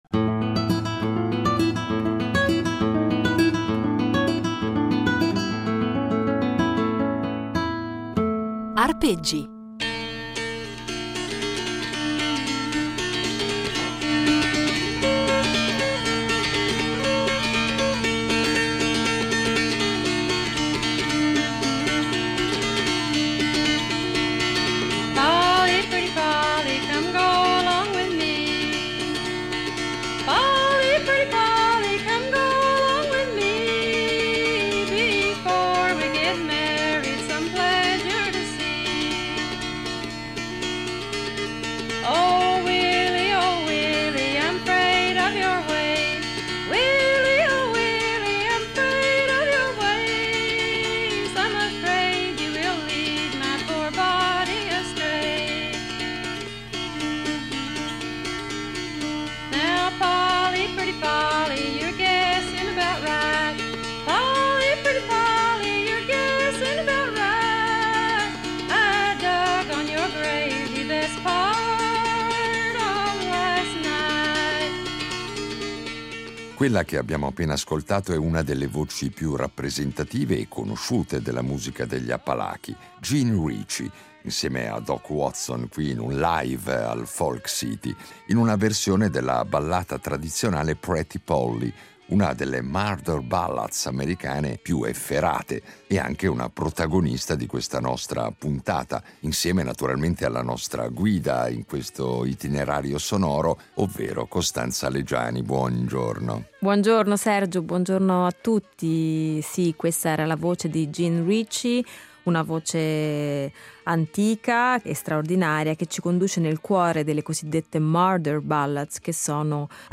Non solo, però, perché i nostri itinerari sonori saranno impreziositi dalle esecuzioni “live”, e quindi inedite